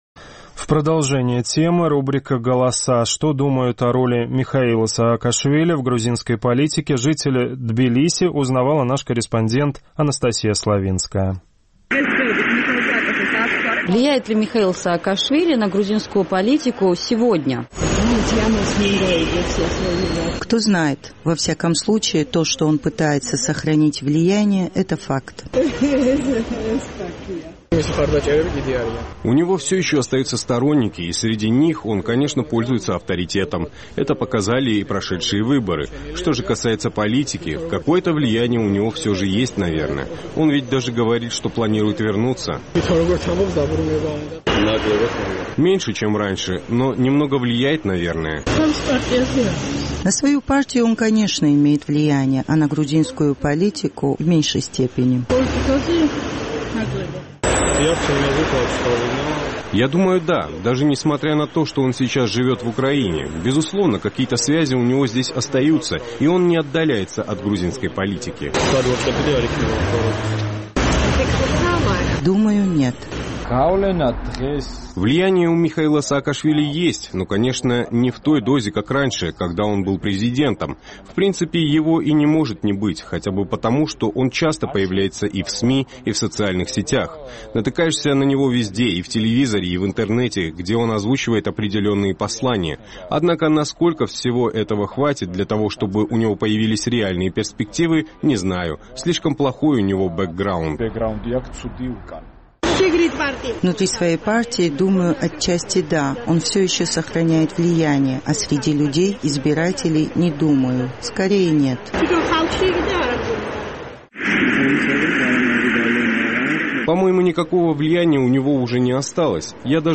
Влияет ли экс-президент Грузии Михаил Саакашвили на грузинскую политику сегодня – с таким вопросом наш корреспондент обратилась к жителям столицы.